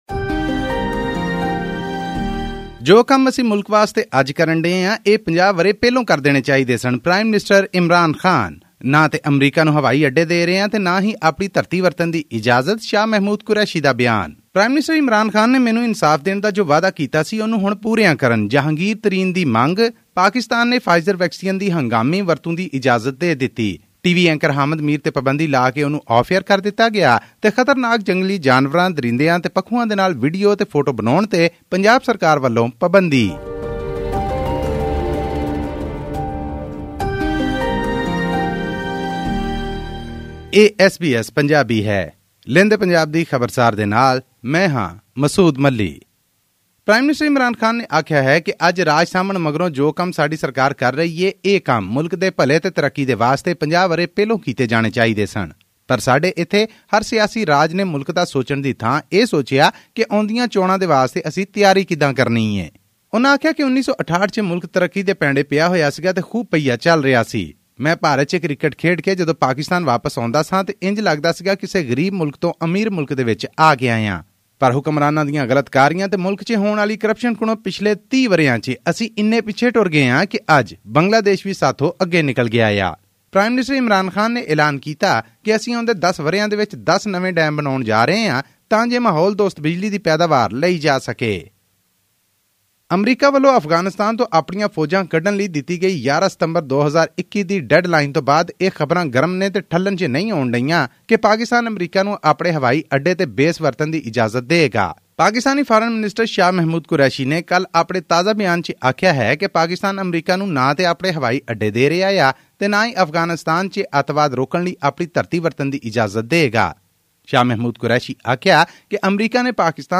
The Drug Regulatory Authority of Pakistan (DRAP) has approved the Pfizer-BioNTech coronavirus vaccine for emergency use in the country. All this and more in our weekly news bulletin from Pakistan.